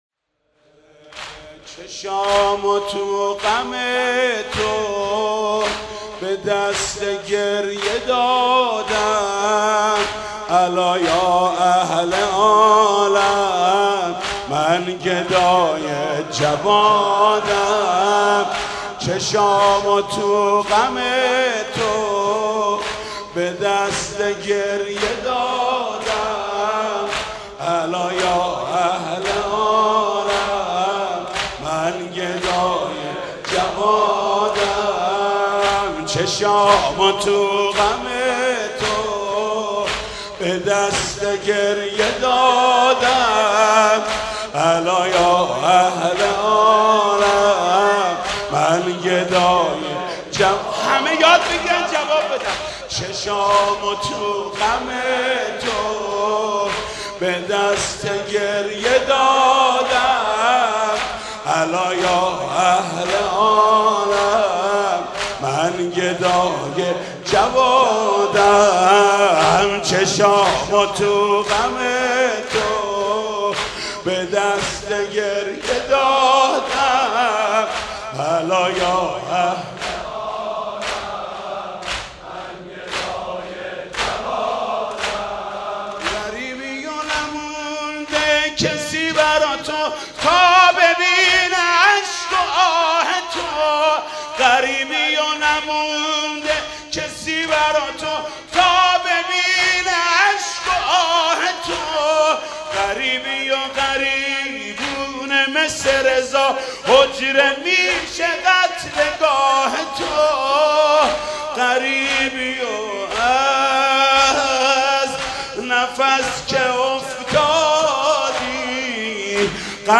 «شهادت امام جواد 1393» زمینه: الا یا اهل عالم من گدای جوادم